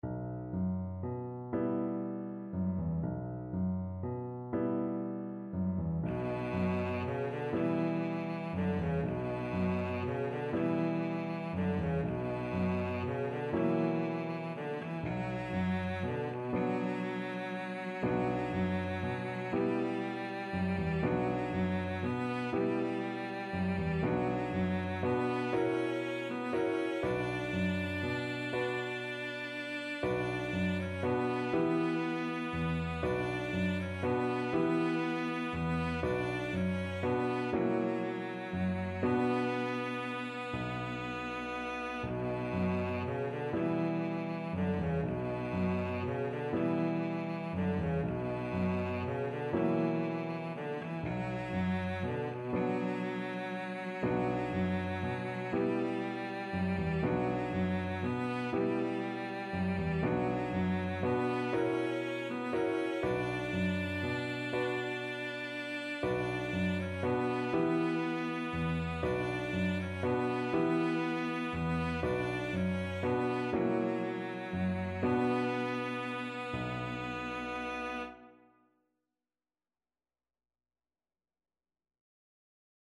Cello
6/8 (View more 6/8 Music)
B minor (Sounding Pitch) (View more B minor Music for Cello )
Gently rocking .=c.40
Traditional (View more Traditional Cello Music)